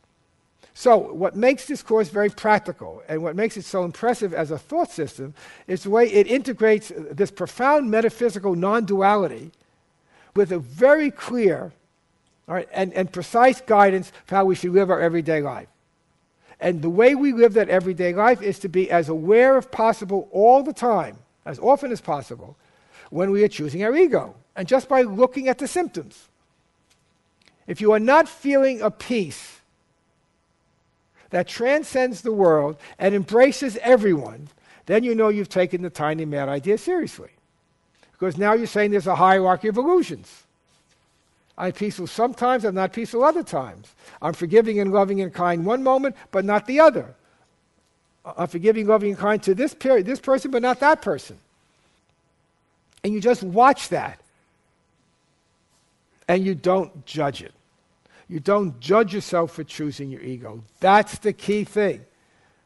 This three-day Academy looks at the holy instant as the correction for the unholy instant when the Son chose to believe in the ego’s lies of sin, guilt, fear, and specialness—a world separate from God.
Original Workshop Date: 01/2010